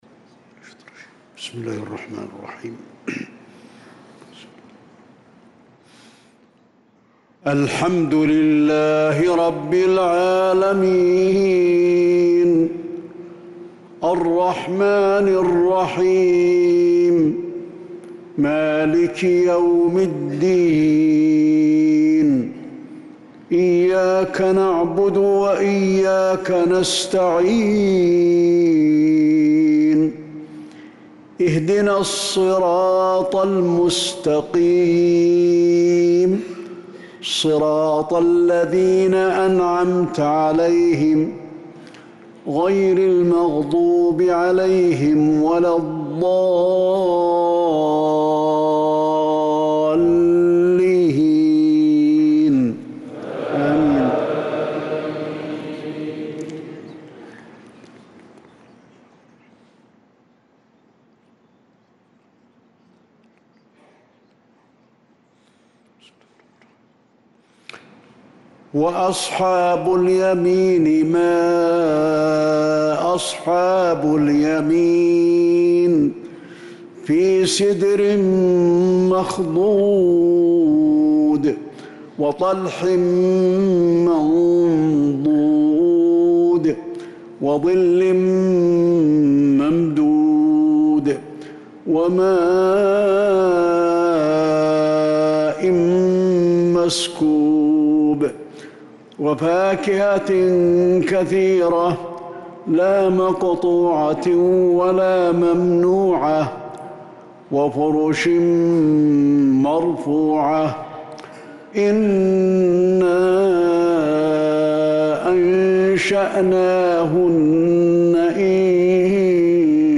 صلاة العشاء للقارئ علي الحذيفي 6 ذو القعدة 1445 هـ
تِلَاوَات الْحَرَمَيْن .